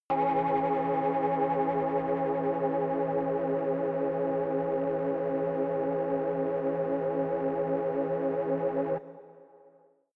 Royalty free music elements: Pads